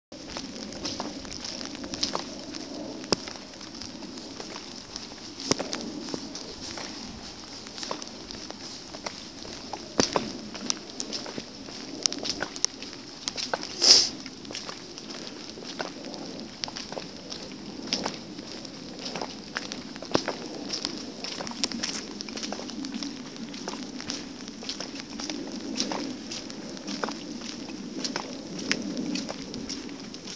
Field Recording #8
Sounds featured: Footsteps, thunder, planes passing overhead, trees shaking in the wind